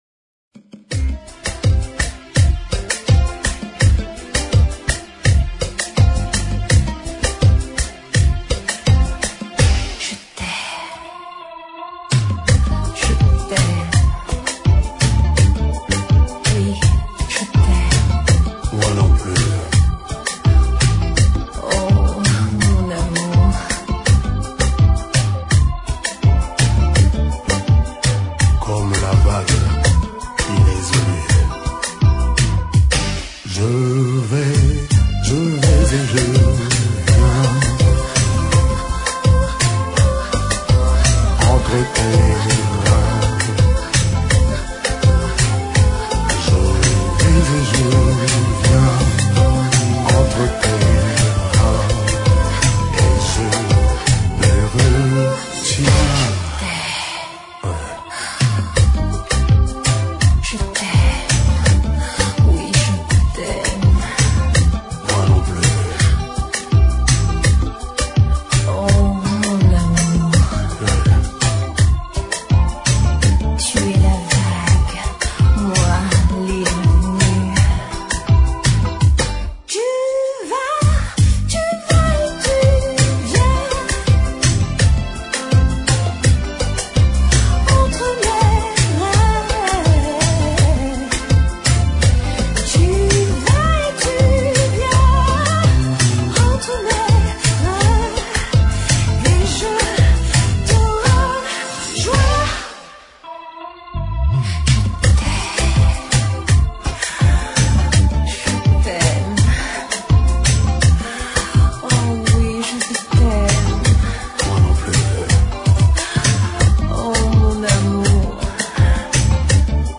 Kizomba Para Ouvir: Clik na Musica.